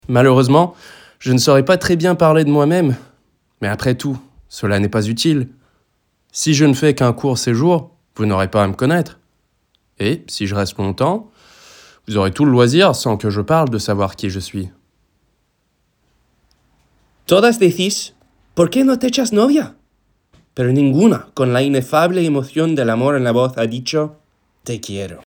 American, positive, bold and confident
Languages Reel